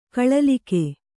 ♪ kaḷalike